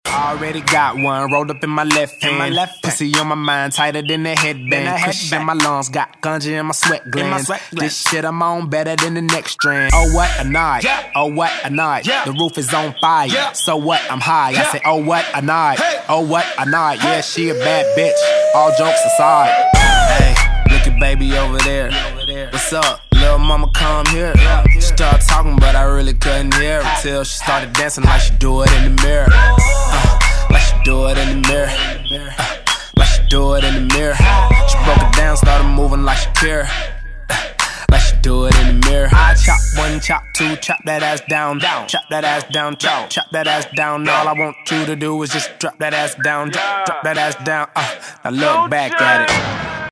• Качество: 192, Stereo
Крутой рэпчик